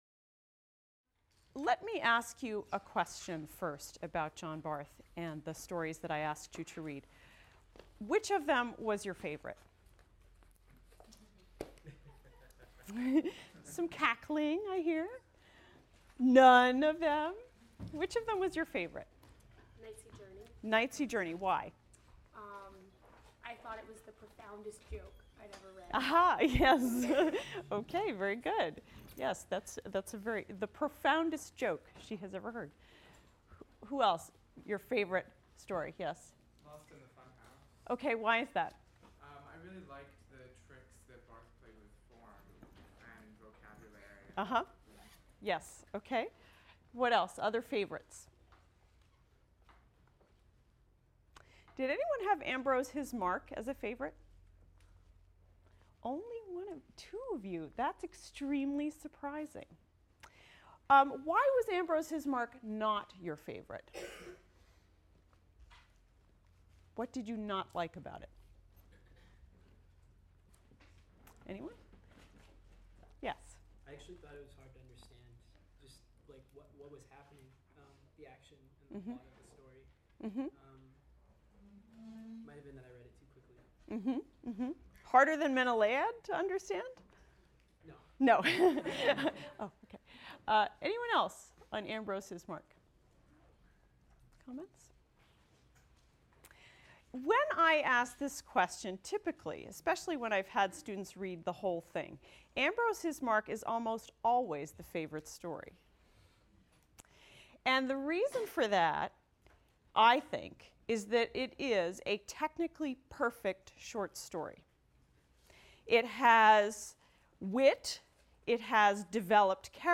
ENGL 291 - Lecture 11 - John Barth, Lost in the Funhouse | Open Yale Courses